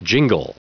Prononciation du mot jingle en anglais (fichier audio)
Prononciation du mot : jingle